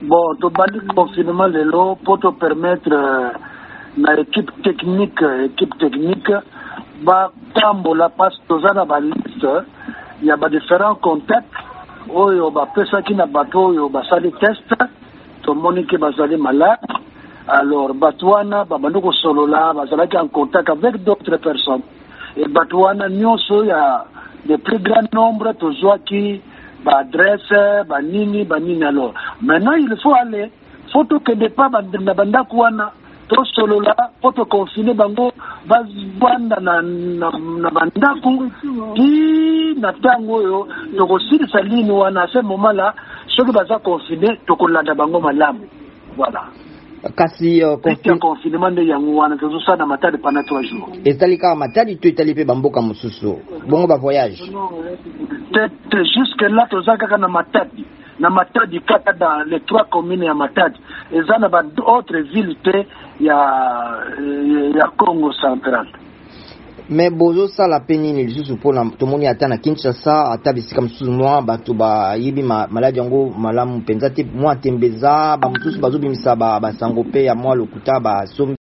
Bakambi ya etuka ya Kongo central engumba ya Matadi etiami na bokangami mikolo misato wuto vendredi mpo na koluka ba contacts ya bakoni 110 ya COVID19. Tolanda ministre ya Santé ya Kongo central, Nestor Matiku Mandiangu.